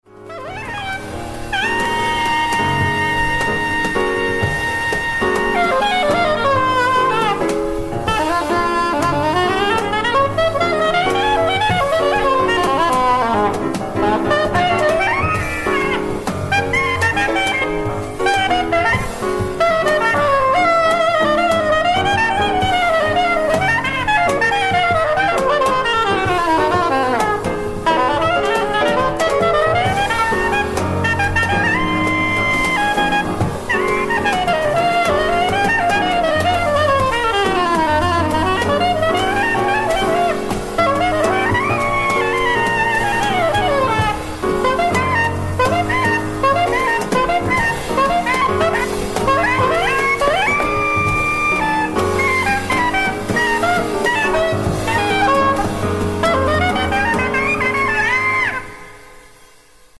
sax soprano e alto, voce
pianoforte
batteria, percussioni, cajon, tabla